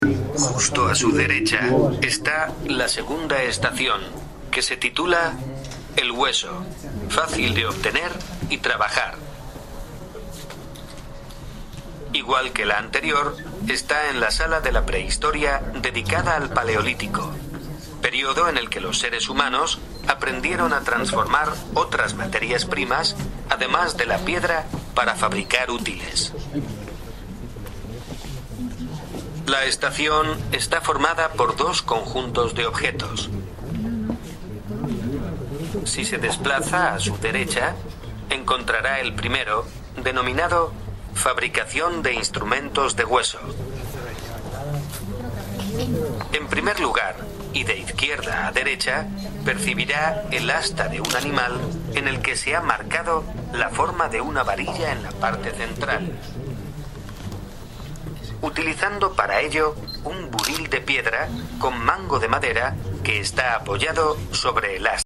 información audiodescriptiva a través de guías multimedia en soporte tablet (archivo MP3).